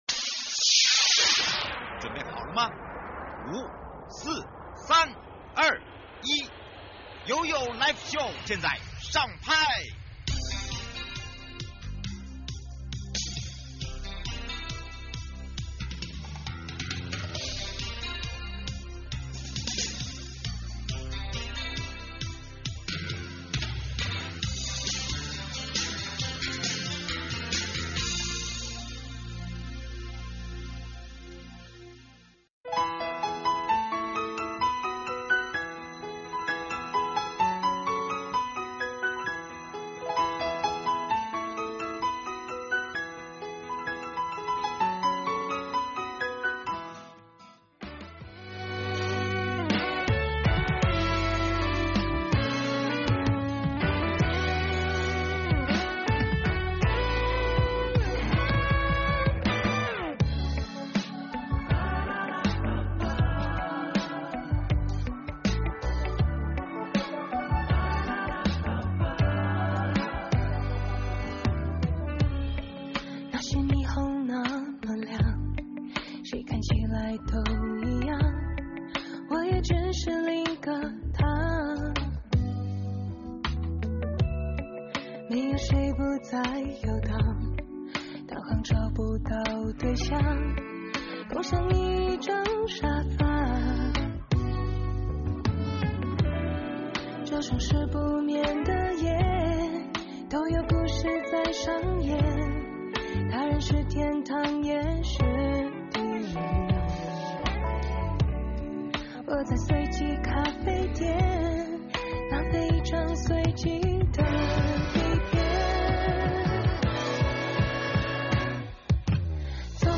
節目內容： 2025 ITF台北國際旅展，逛展小撇步、國旅餐券、住宿券、主題遊程任你選！ 受訪者